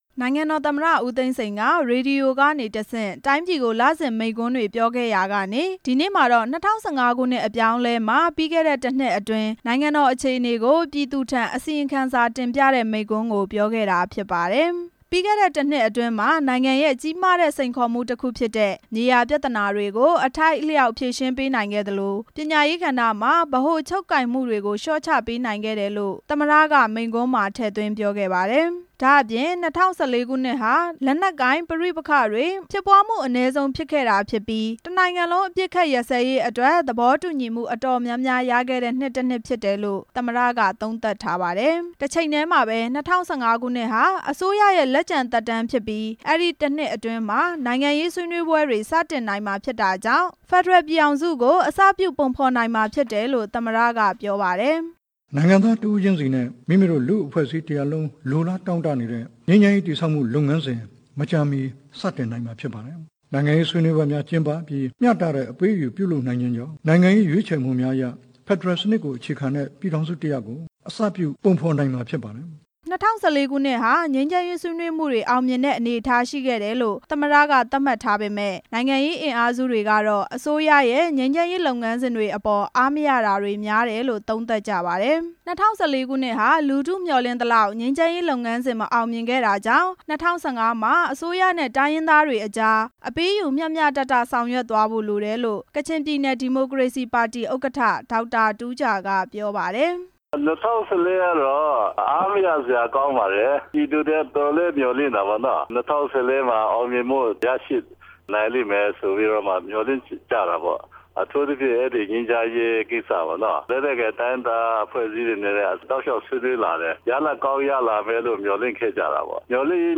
နိုင်ငံတော်သမ္မတ ဦးသိန်းစိန်က ၂၀၁၄ ခုနှစ်အတွင်း နိုင်ငံရေး၊ စီးပွားရေး၊ လူမှုရေးအခြေအနေတွေနဲ့ အစိုးရအဖွဲ့ရဲ့ ဆောင်ရွက်မှုတွေကို "ပြည်သူသို့ အစီရင်ခံစာတင်ပြချက်"ဆိုတဲ့ခေါင်းစဉ်နဲ့ ရေဒီယိုကနေတဆင့် ဒီနေ့ မိန့်ခွန်းပြောကြား ခဲ့ပါတယ်။ အဲဒီမိန့်ခွန်းမှာ ၂၀၁၅ ခုနှစ်ဟာ မြန်မာ့နိုင်ငံရေးသမိုင်းမှာ သမိုင်းတွင်မယ့် လုပ်ငန်းစဉ်တွေကို အခြေတည်မယ့် နှစ်တစ်နှစ်ဖြစ်ကြောင်းလည်း ပြောလိုက်ပါတယ်။